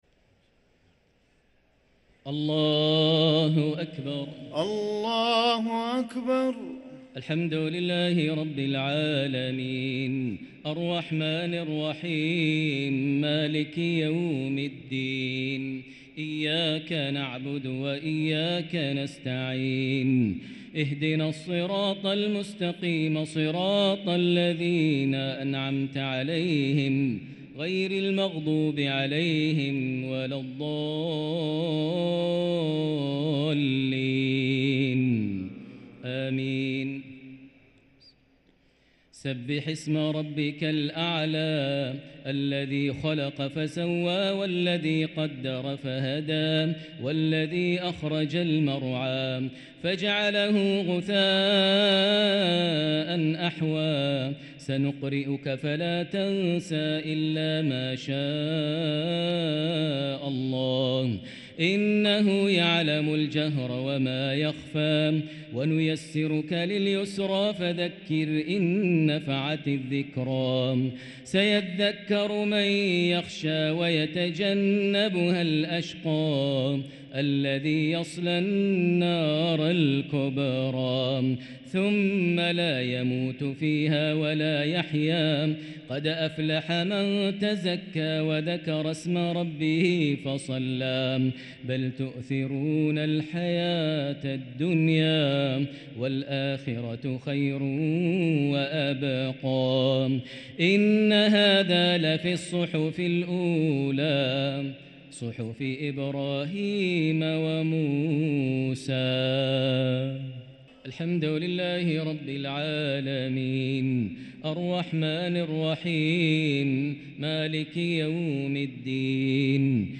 الشفع و الوتر ليلة 14 رمضان 1444هـ > تراويح 1444هـ > التراويح - تلاوات ماهر المعيقلي